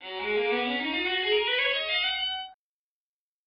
Synthetically adding reverb to a viola signal and trying to estimate the original form.
observation has reverb.